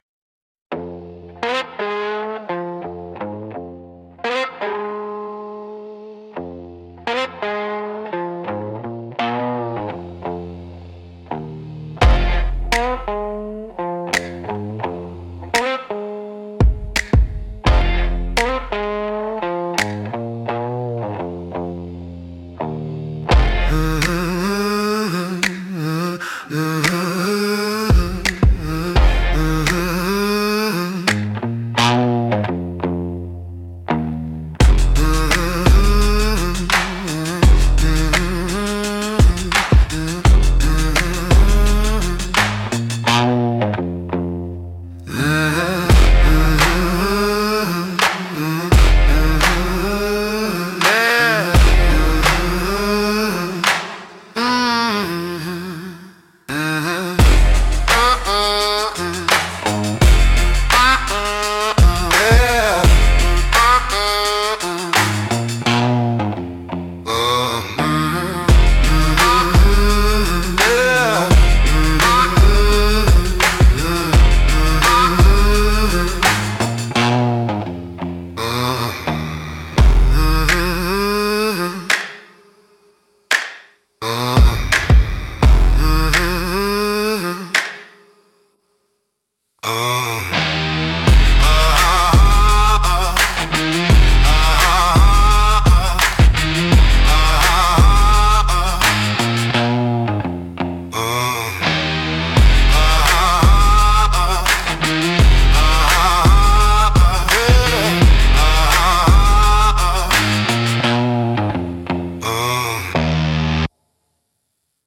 Instrumental - Swampfire Protocol 1.54